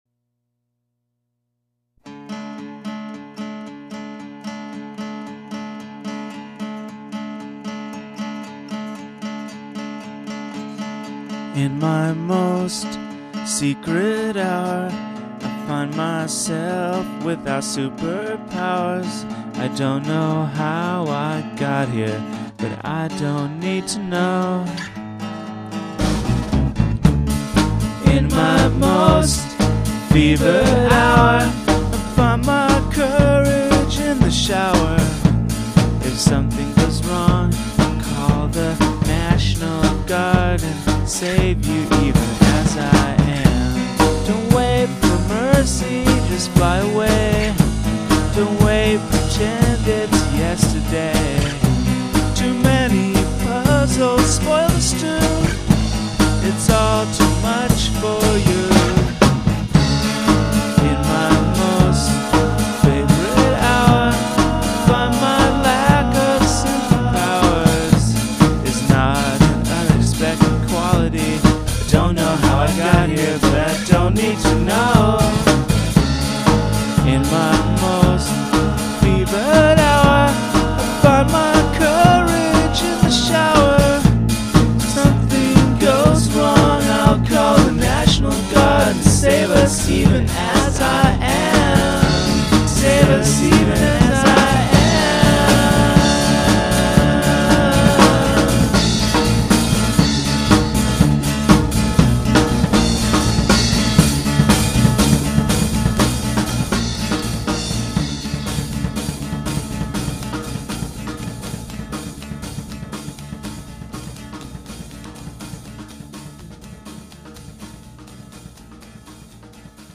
Soothing Audio for Your Modern Lifestyle